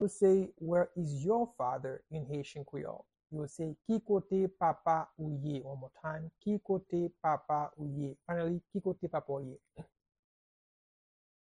How-to-say-Where-is-your-father-in-Haitian-Creole-–-Ki-kote-papa-ou-ye-pronunciation-by-a-Haitian-teacher.mp3